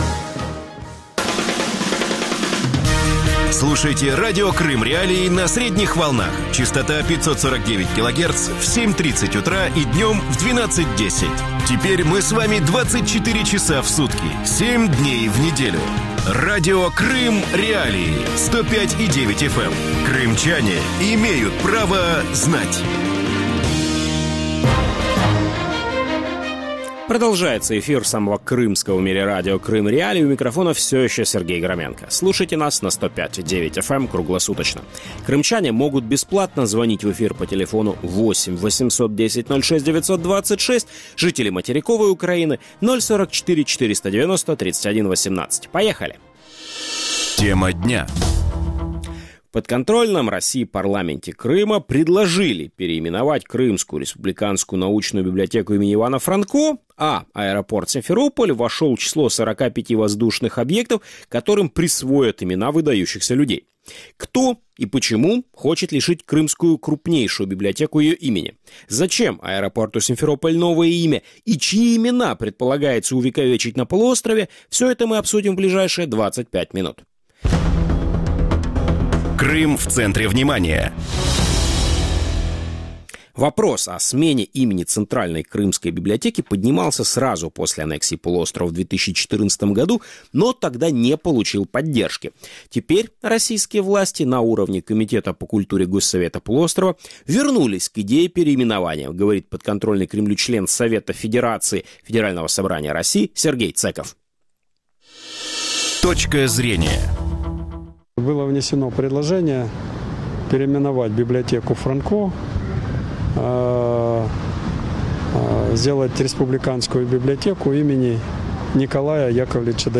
крымский историк
крымский политолог и историк.